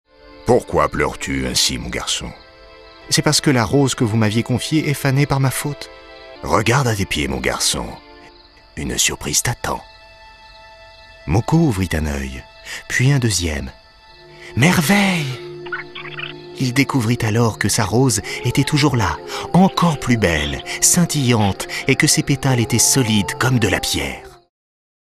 Prestation voix-off sur "Moko" : Tonalités enfant, jeu, poésie, doux, conteur, posé
Plusieurs personnages dont le conteur.
Dans la peau de plusieurs personnages, dont le conteur, j’ai su donner vie à chaque protagoniste avec une voix médium, médium grave et grave.
Pour ce qui est du ton de la voix, j’ai varié entre un ton pour enfant, ludique, composé, poétique, doux, conteur et posé.